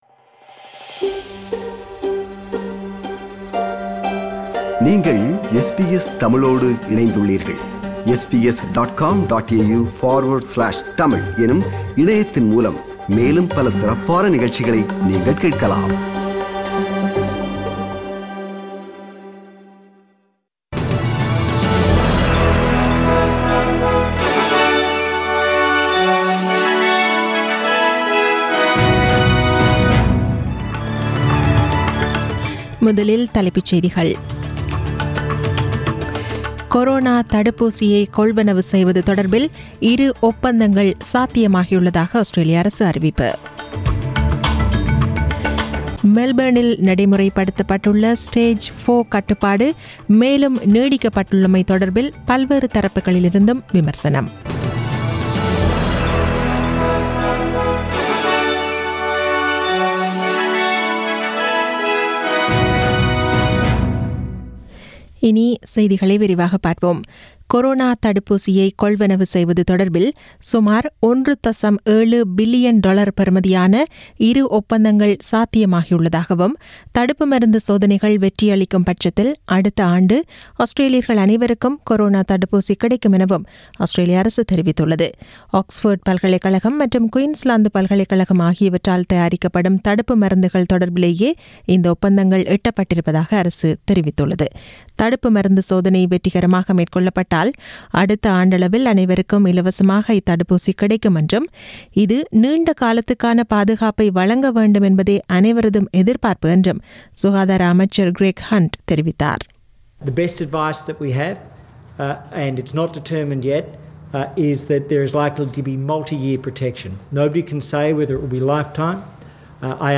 நமது SBS தமிழ் ஒலிபரப்பில் இன்று திங்கள் (07 செப்டம்பர் 2020 ) இரவு 8 மணிக்கு ஒலித்த ஆஸ்திரேலியா குறித்த செய்திகள்.